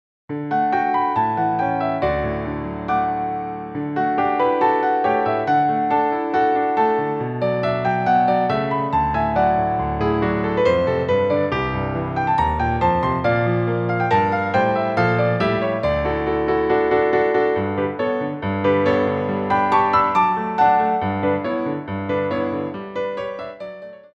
Theme Songs from Musicals for Ballet Class
Piano Arrangements
2/4 (8x8)